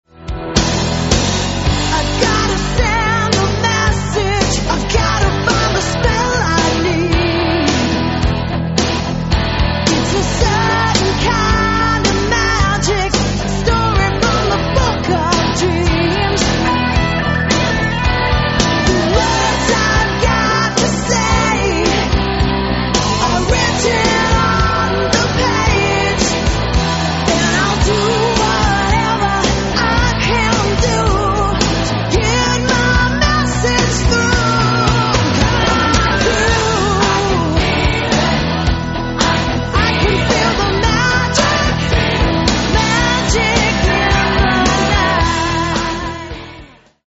Sounds like: Bon Jovi.